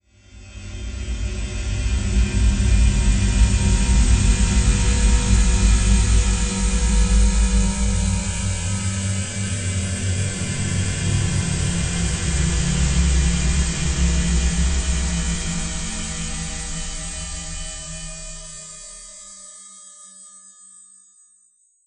Spectral Drone 02.wav